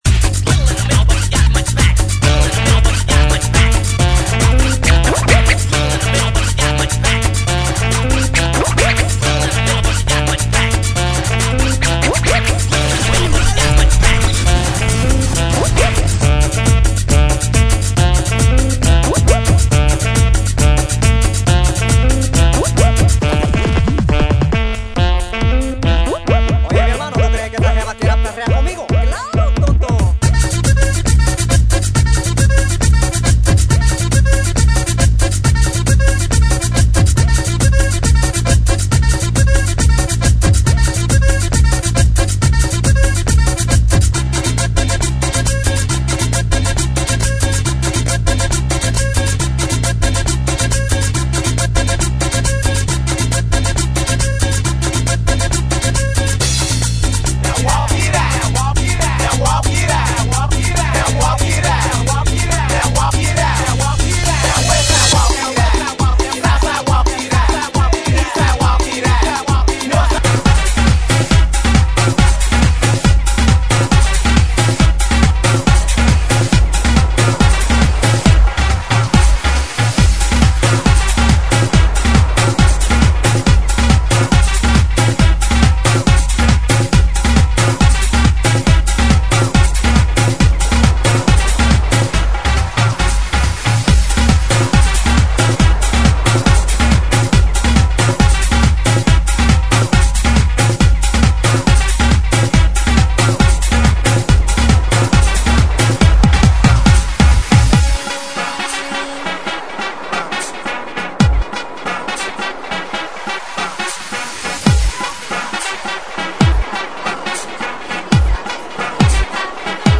GENERO: TRIBAL HOUSE